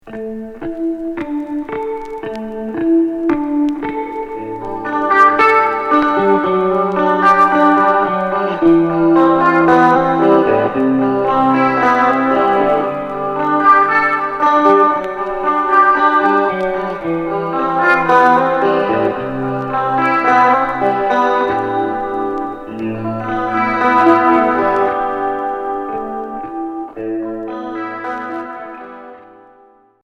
Cold rock